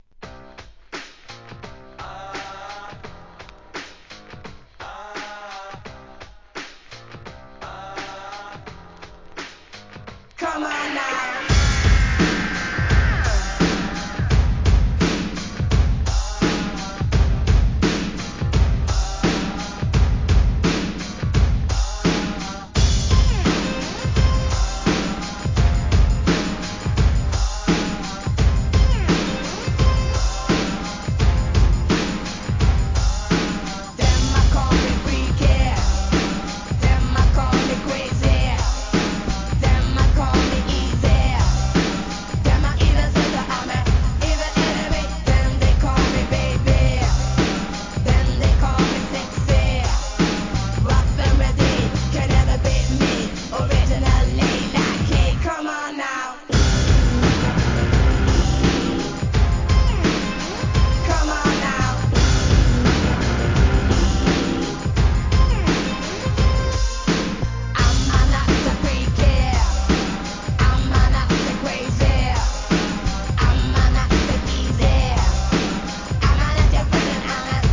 HIP HOP/R&B
エネレギッシュなBEATで歌うROCKIN'ナンバー!!